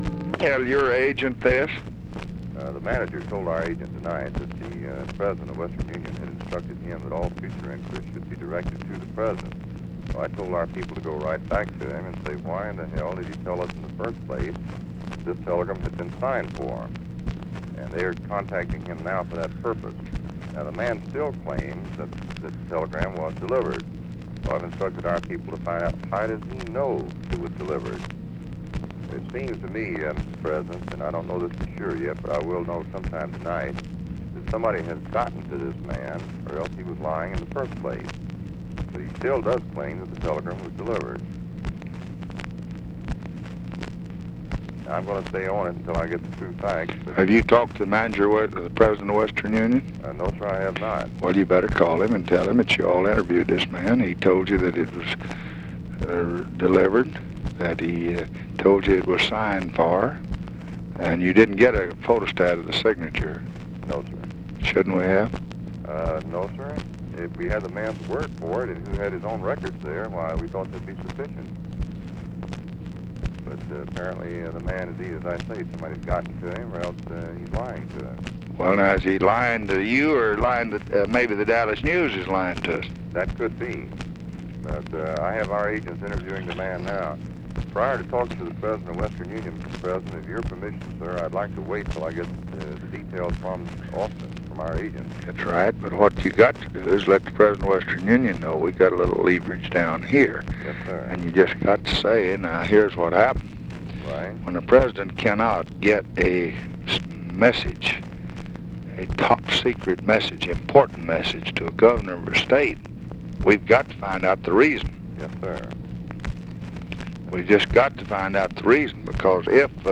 Conversation with CARTHA DELOACH, March 15, 1966
Secret White House Tapes